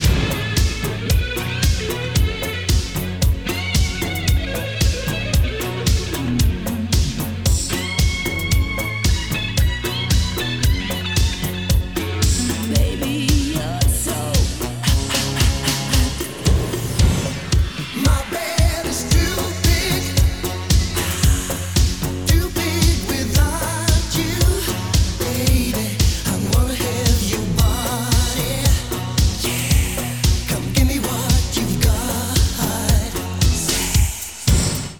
• Качество: 190, Stereo
80-е